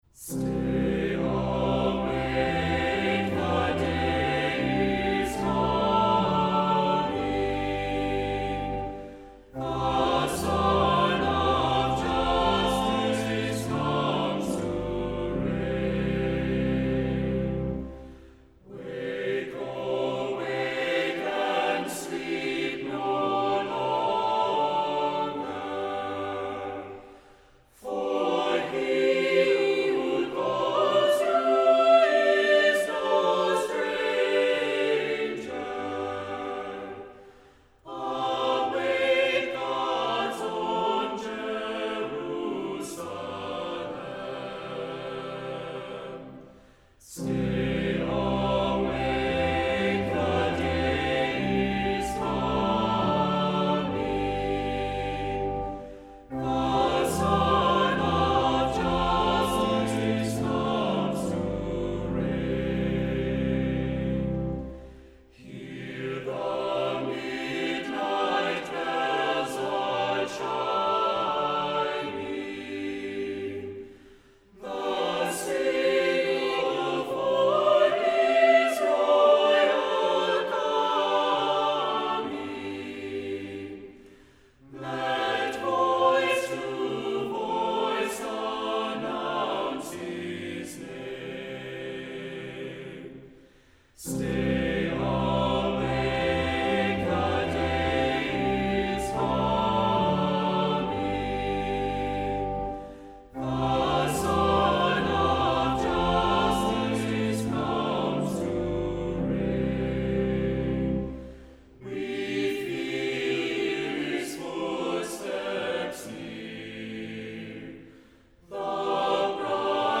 Accompaniment:      Reduction
Music Category:      Choral
Advent hymn
The verses are sung by cantor or choir or both.